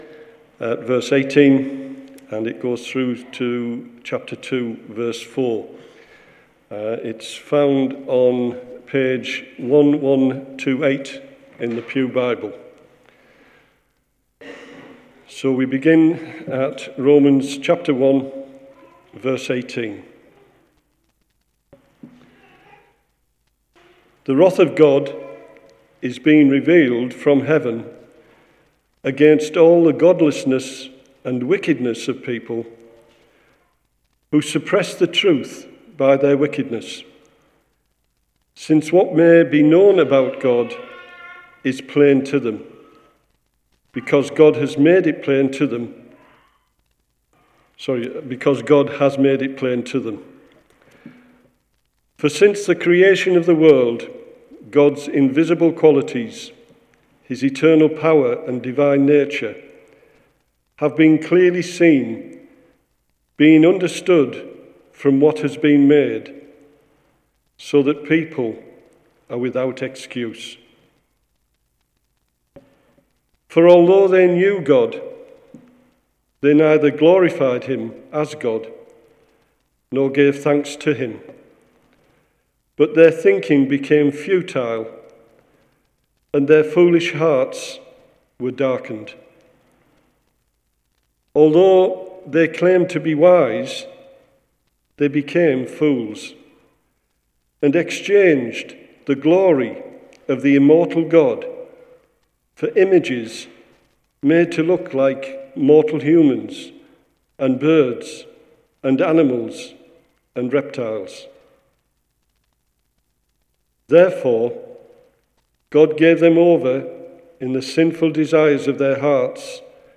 2:4 Tagged with Morning Service Audio